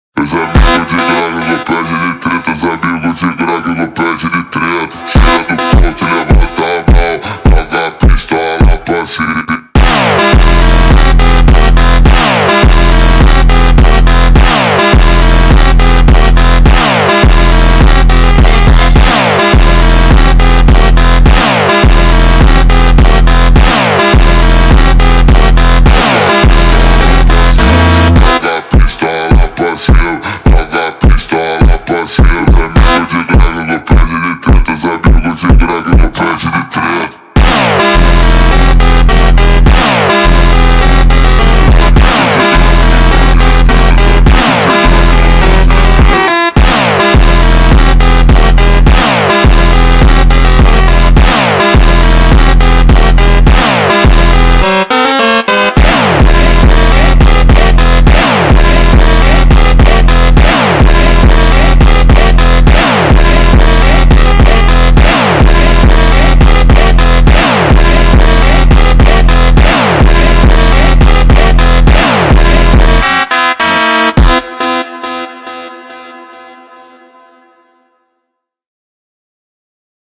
در نسخه کند شده و Slowed
فانک